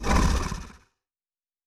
Large Creature 13 - Short 3.wav